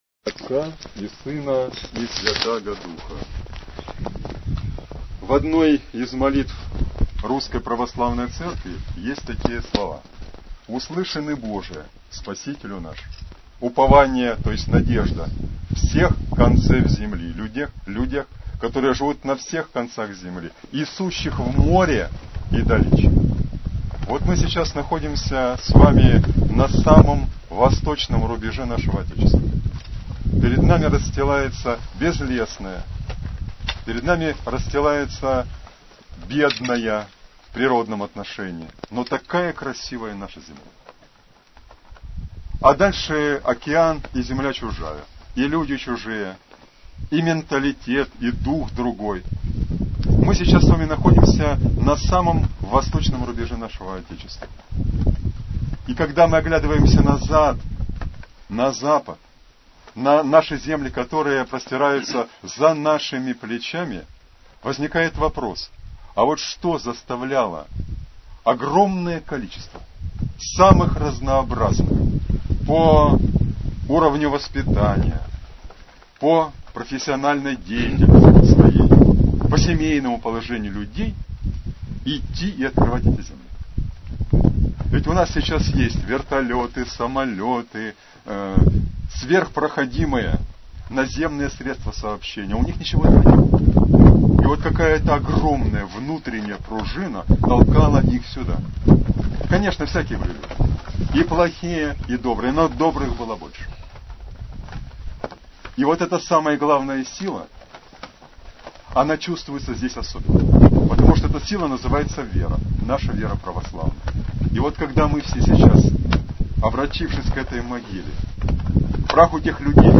Слово архиеископа Игнатия после заупокойной литии на о.Шумшу.